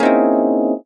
描述：一个平移的不和谐的无人机，产生一种紧张感
Tag: 高音调 不和谐 紧张 无人驾驶飞机 电影